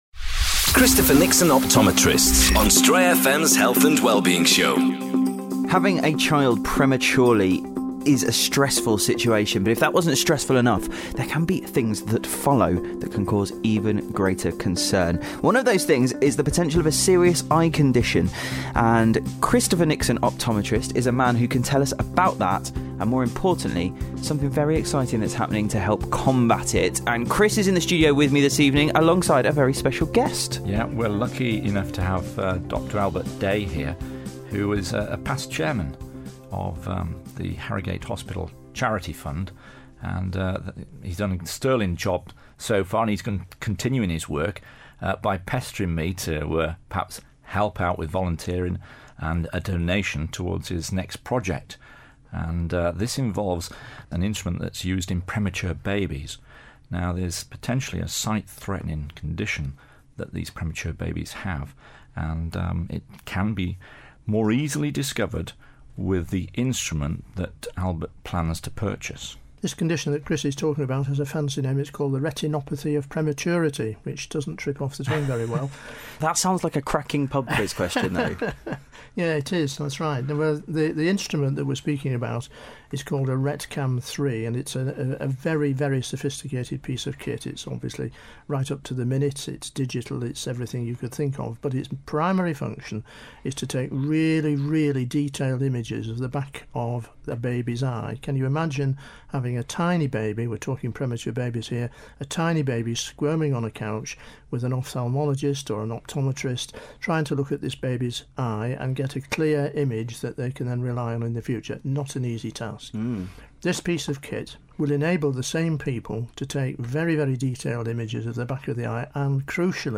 to the Stray FM studio to chat about a forthcoming gala